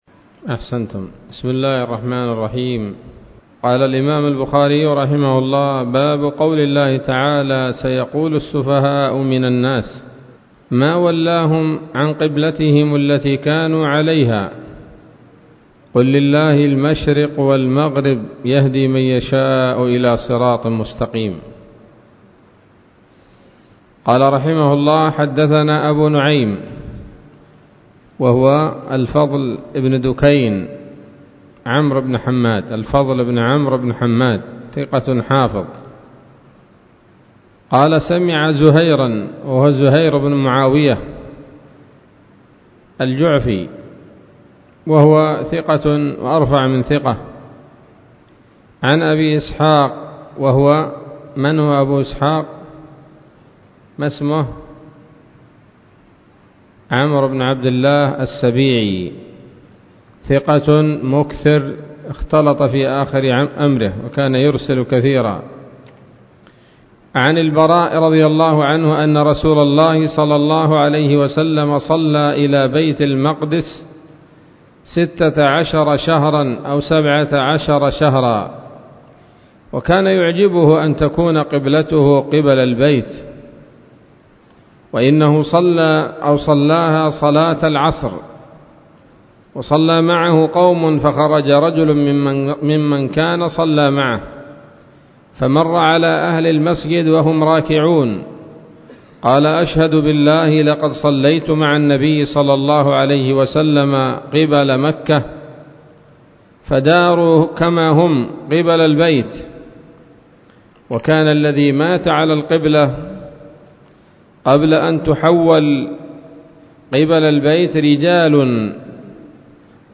الدرس الثالث عشر من كتاب التفسير من صحيح الإمام البخاري